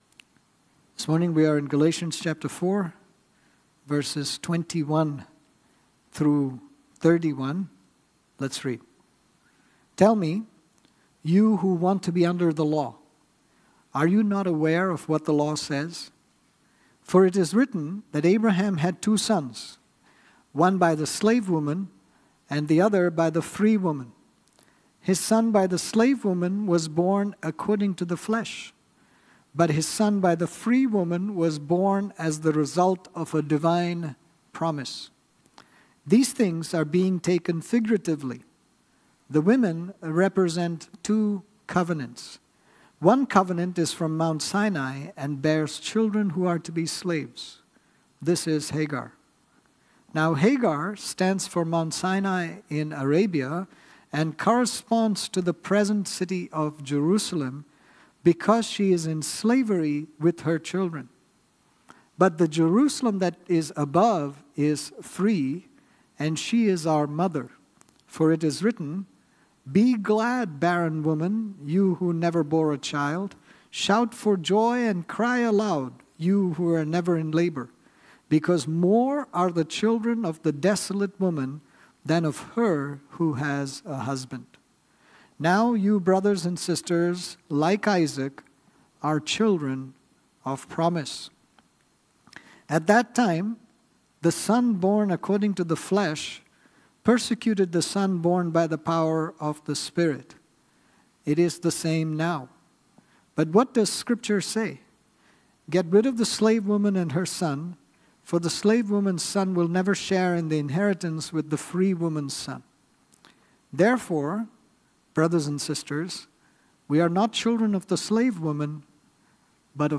Sermons | New Life Fellowship Church